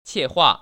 怯话[qièhuà]